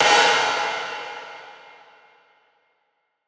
Big Drum Hit 16.wav